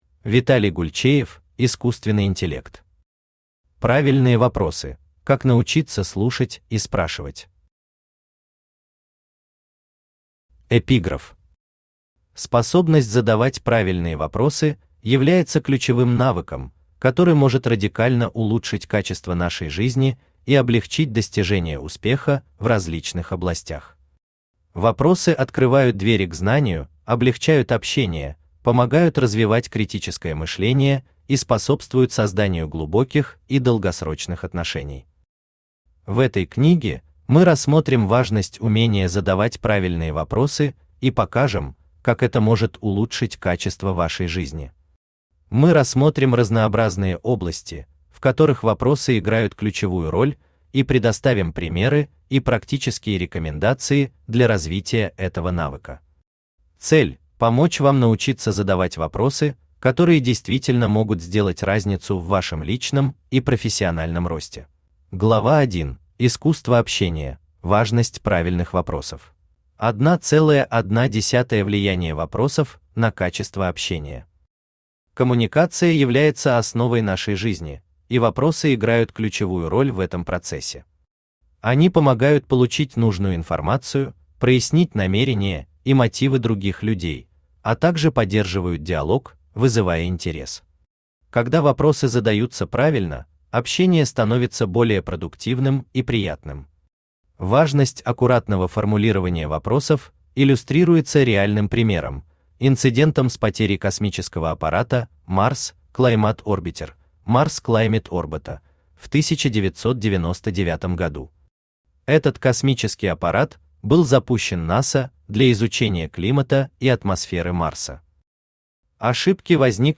Aудиокнига Правильные вопросы: как научиться слушать и спрашивать Автор Виталий Александрович Гульчеев Читает аудиокнигу Авточтец ЛитРес.